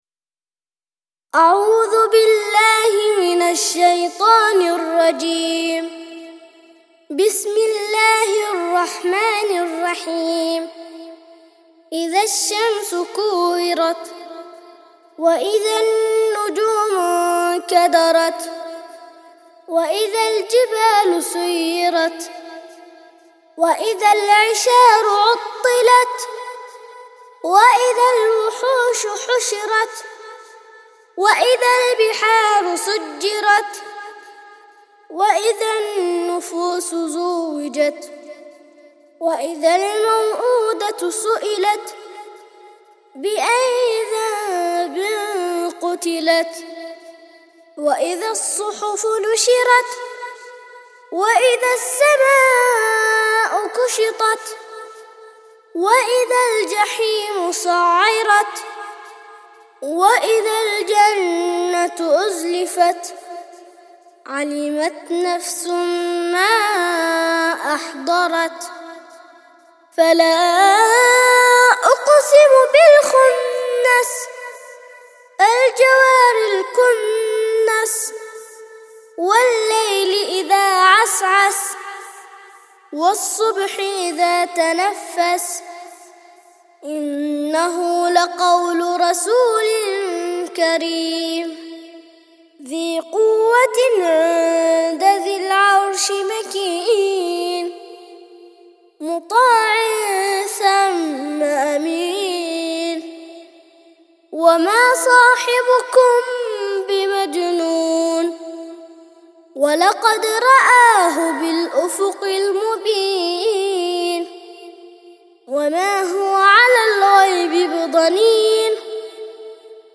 81- سورة التكوير - ترتيل سورة التكوير للأطفال لحفظ الملف في مجلد خاص اضغط بالزر الأيمن هنا ثم اختر (حفظ الهدف باسم - Save Target As) واختر المكان المناسب